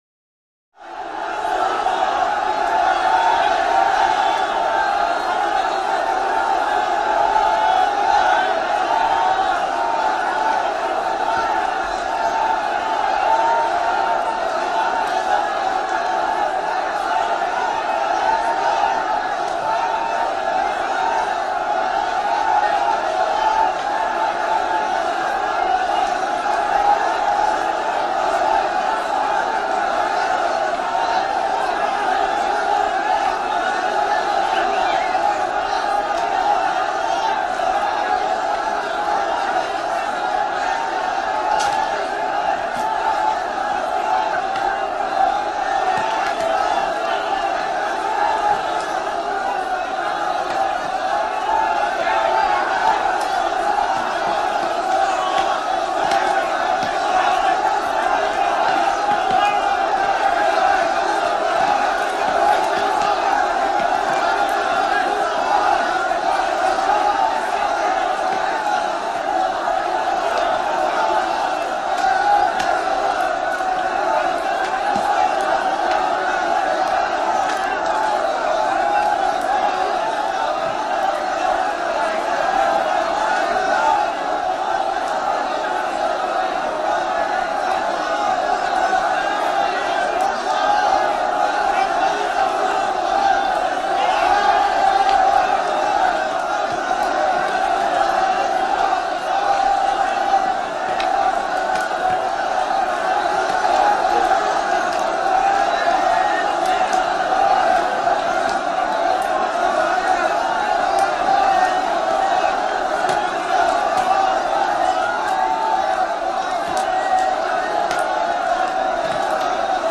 Exterior Seething Large Arab Crowd.